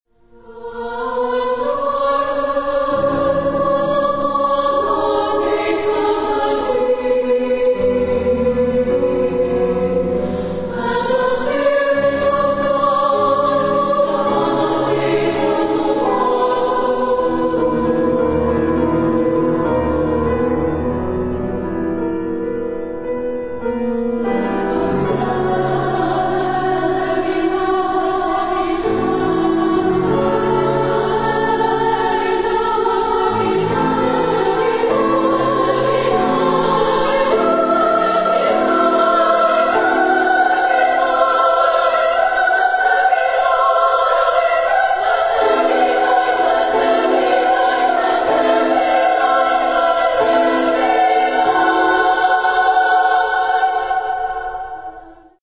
Genre-Style-Form: Sacred ; Contemporary
Type of Choir: SSA  (3 women voices )
Instrumentation: Piano  (1 instrumental part(s))